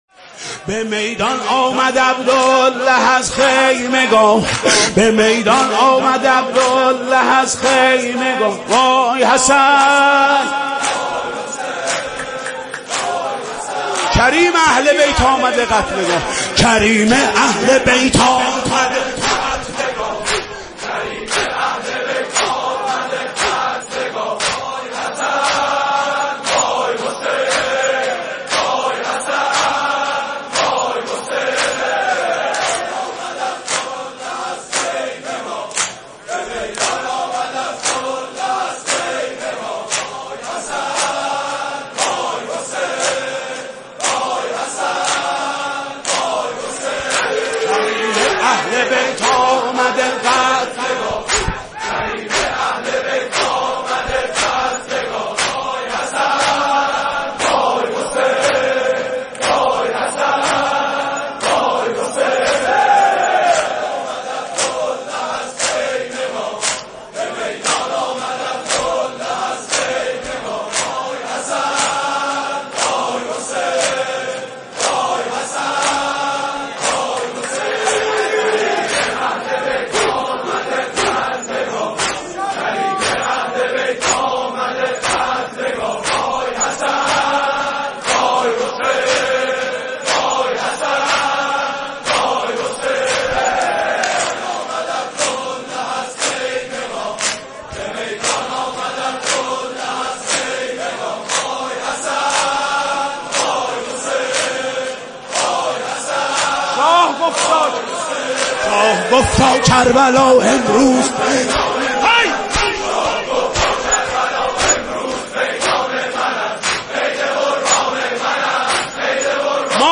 دودمه